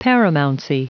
Prononciation du mot paramountcy en anglais (fichier audio)
Prononciation du mot : paramountcy